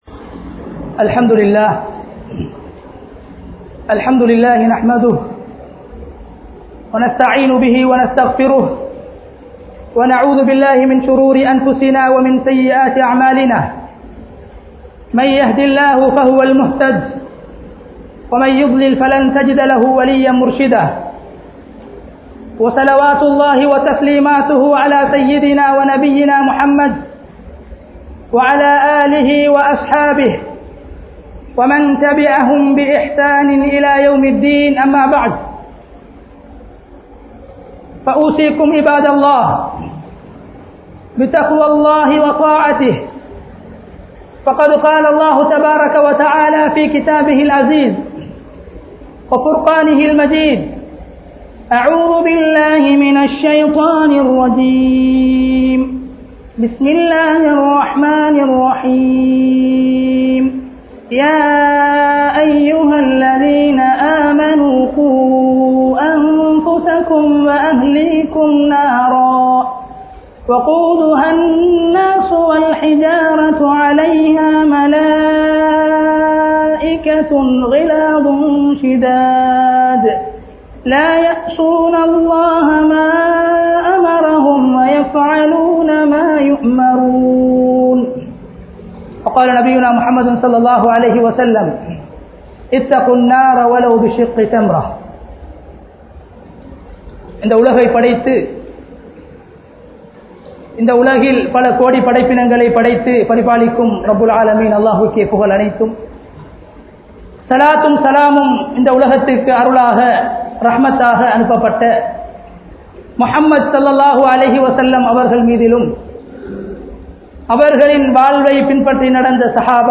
Narahai Haramaakkum 17 Vidayangal [நரகை ஹரமாக்கும் 17 விடயங்கள்] | Audio Bayans | All Ceylon Muslim Youth Community | Addalaichenai